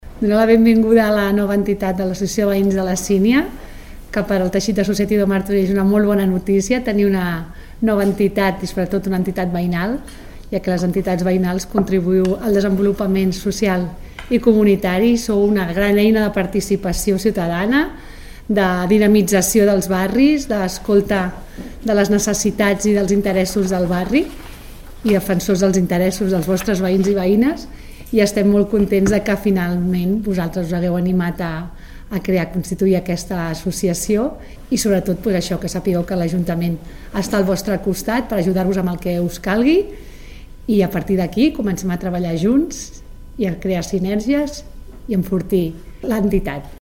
Àngels Soria, regidora de Teixit Associatiu